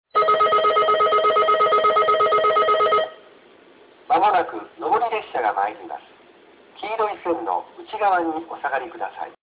☆旧放送
上り列車接近放送　男声